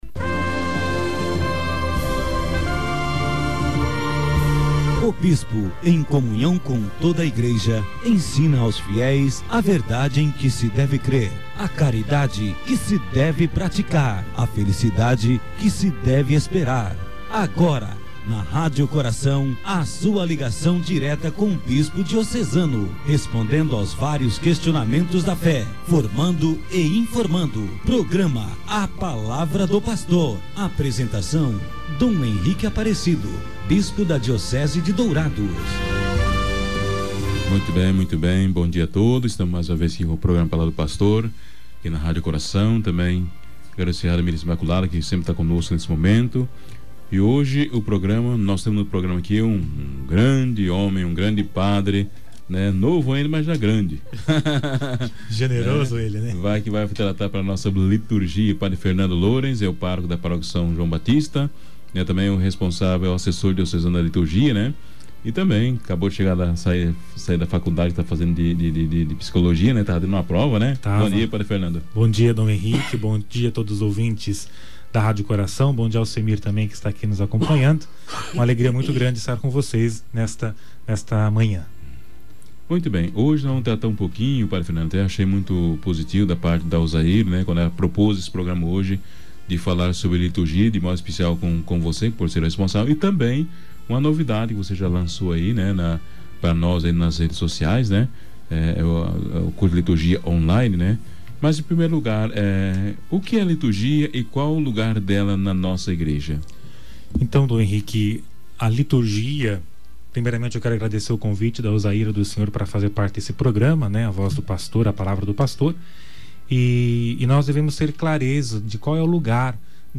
Dom Henrique Aparecido de Lima, religioso redentorista e bispo da Diocese de Dourados, apresentou na sexta-feira (13/09) o programa 'A Palavra do Pastor'.